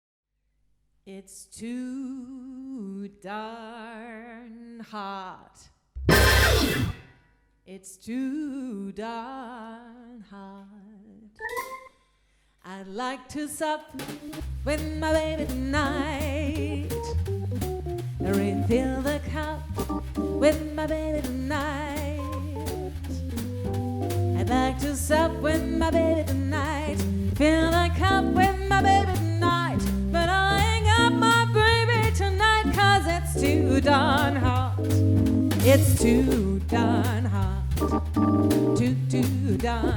chant
trompette
piano.